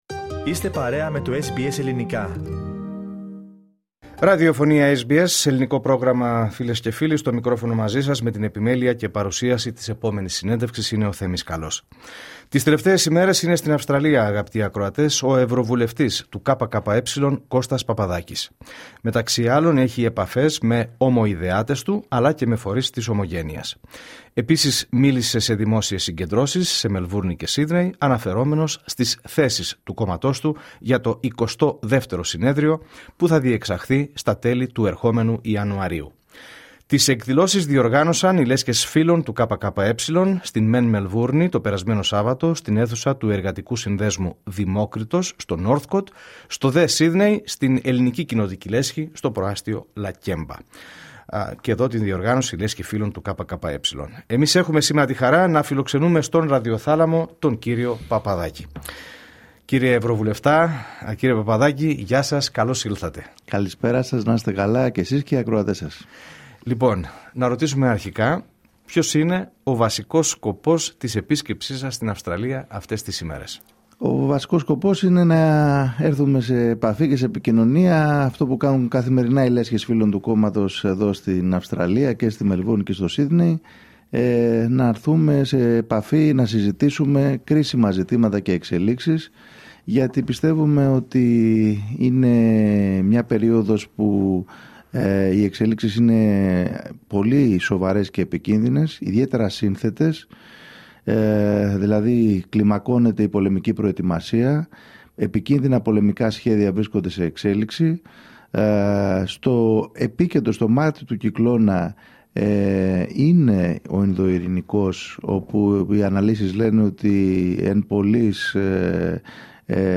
Η ψήφος των Ελλήνων του εξωτερικού και ο ρόλος της ομογένειας στις ελληνικές εκλογικές διαδικασίες, οι ελληνοαυστραλιανές σχέσεις καθώς και οι προκλήσεις και τα προβλήματα που αντιμετωπίζουν οι εργαζόμενοι, βρέθηκαν στο επίκεντρο της συνέντευξης που παραχώρησε στο Πρόγραμμά μας SBS Greek, ο ευρωβουλευτής του ΚΚΕ, Κώστας Παπαδάκης.